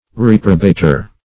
Reprobater \Rep"ro*ba`ter\ (-b?`t?r), n. One who reprobates.